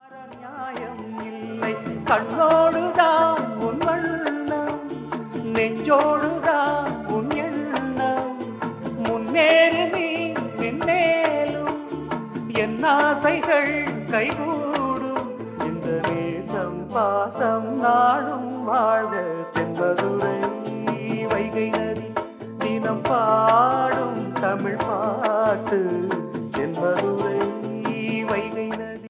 romantic ringtone